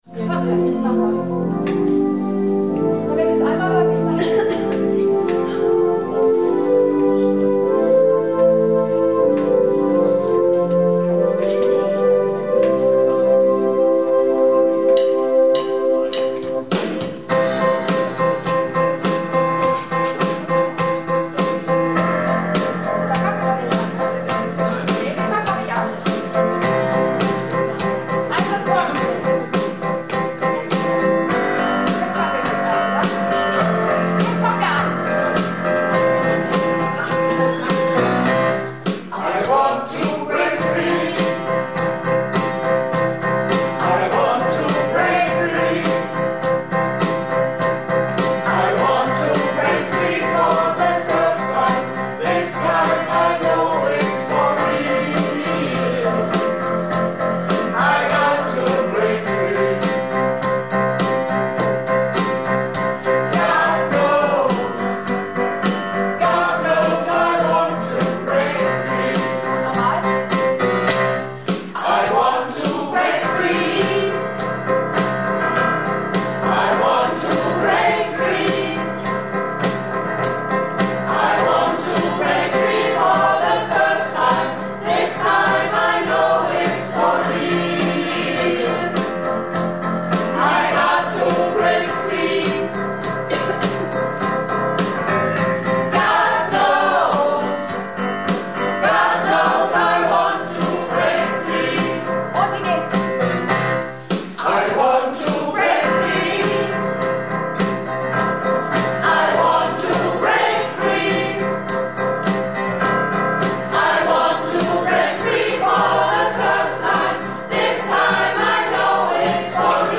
Projektchor "Keine Wahl ist keine Wahl" - Probe am 24.04.19